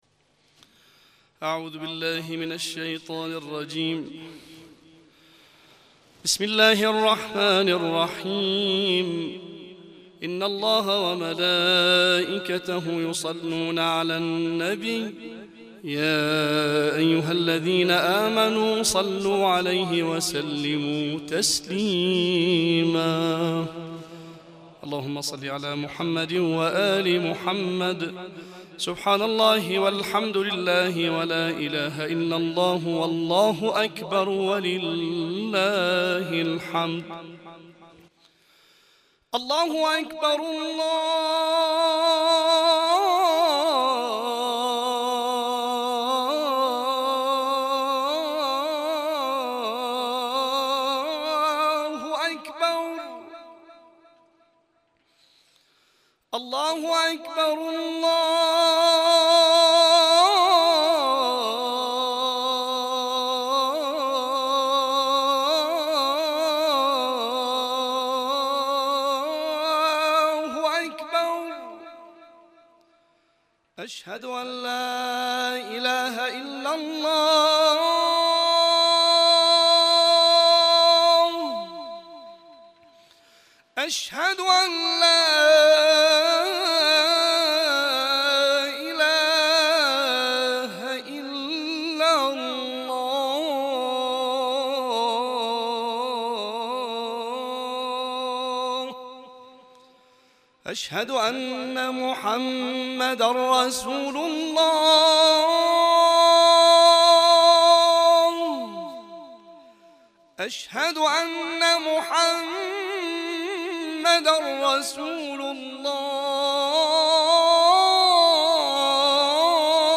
اذان
في حرم الإمام الرضا (ع)